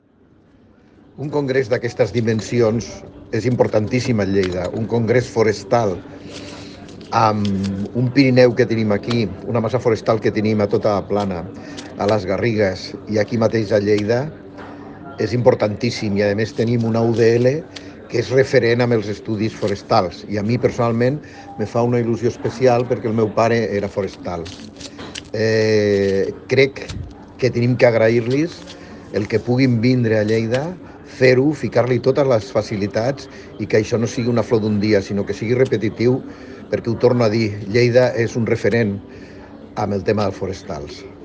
Per la seva banda, el president de Turisme de Lleida, Paco Cerdà, ha donat la benvinguda al congrés i ha remarcat l’oportunitat que es faci a Lleida. Tall de veu de Paco Cerdà El Congrés Forestal Espanyol és l’esdeveniment més important que organitza la Societat Espanyola de Ciències Forestals (SECF) i se celebra cada quatre anys.
tall-de-veu-del-tinent-dalcalde-paco-cerda